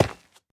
Minecraft Version Minecraft Version latest Latest Release | Latest Snapshot latest / assets / minecraft / sounds / block / ancient_debris / break5.ogg Compare With Compare With Latest Release | Latest Snapshot
break5.ogg